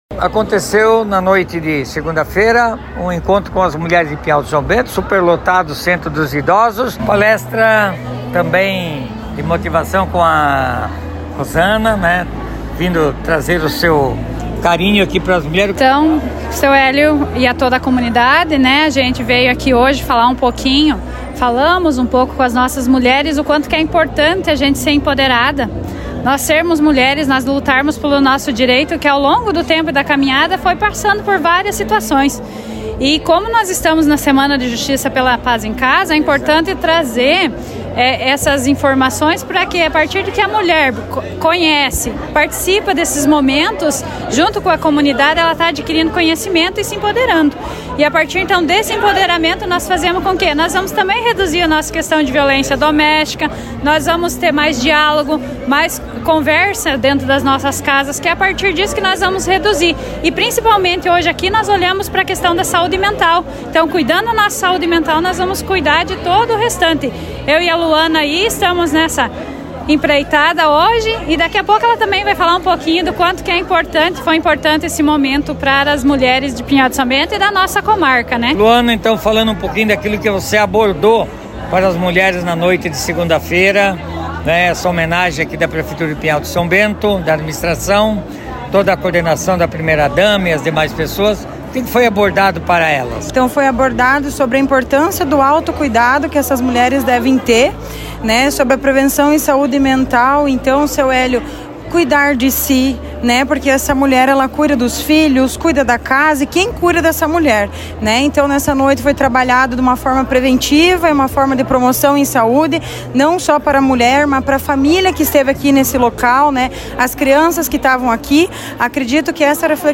Em alusão ao Dia Internacional da Mulher, um evento especial foi realizado em Pinhal de São Bento, reunindo autoridades e profissionais para destacar a importância da data.